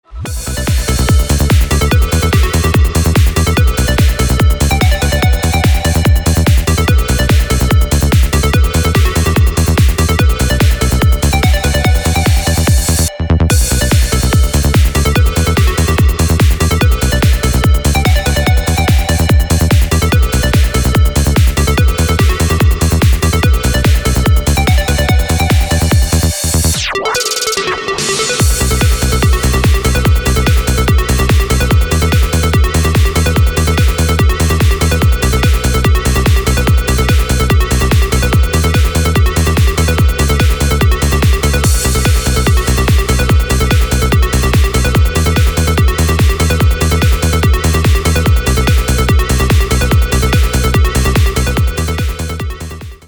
• Качество: 256, Stereo
громкие
dance
Electronic
EDM
электронная музыка
без слов
club
Trance
psy-trance